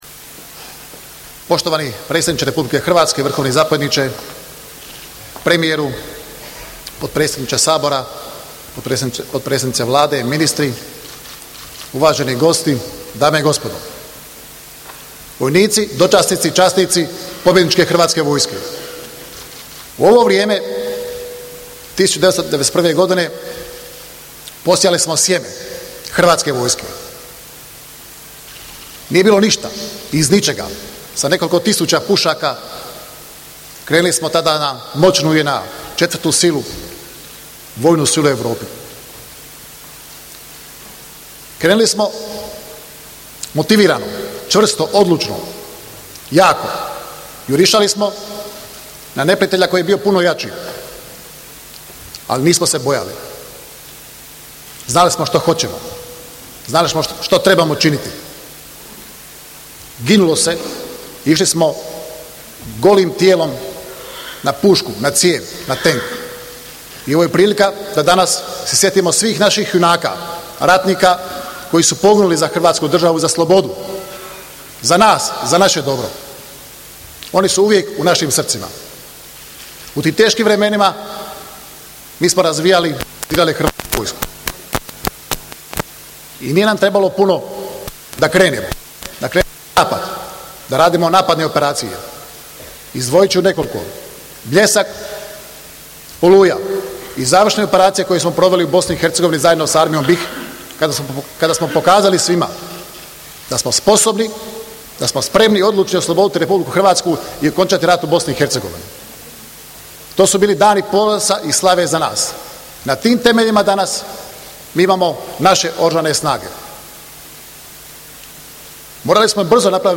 Predsjednik Vlade Republike Hrvatske Zoran Milanović sudjelovao je na Svečanoj akademiji, u povodu obilježavanja Dana Oružanih snaga Republike Hrvatske i Dana kopnene vojske.
Govor ministra obrane Ante Kotromanovića